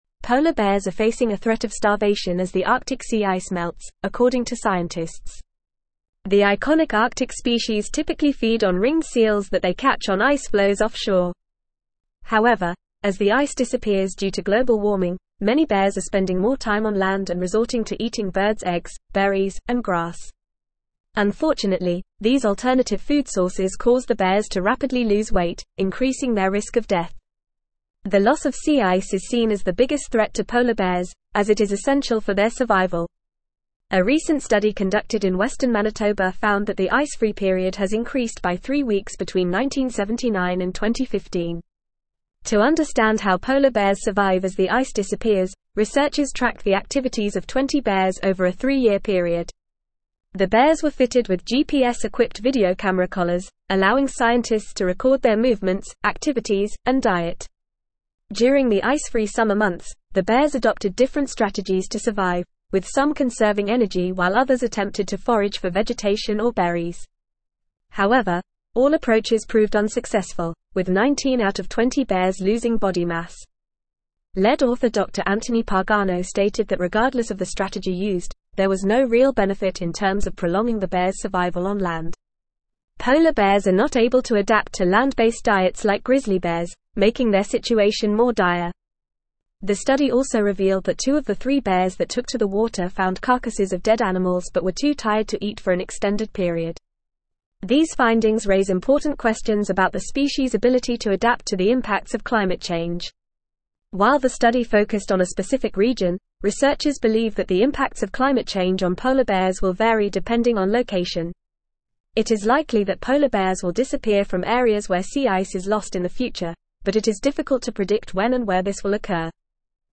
Fast
English-Newsroom-Advanced-FAST-Reading-Polar-bears-face-starvation-as-Arctic-sea-ice-melts.mp3